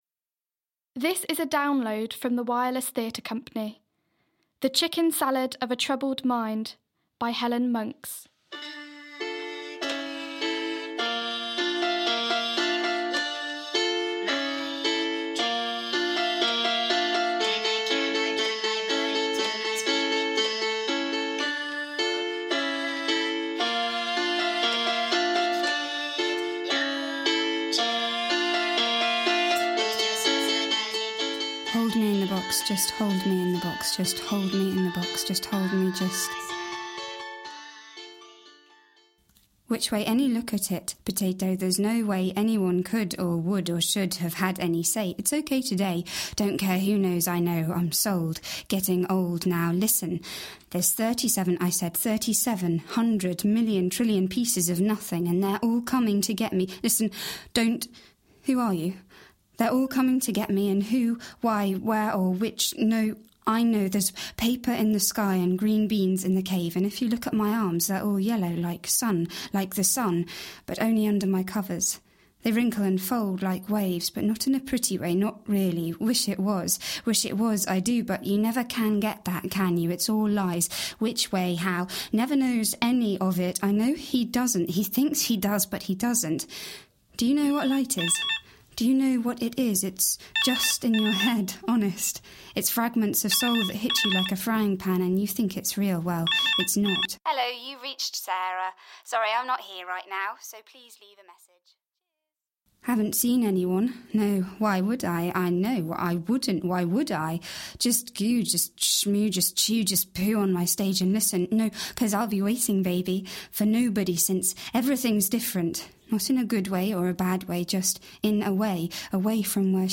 The Chicken Salad of a Troubled Mind - Radio Drama [Drama]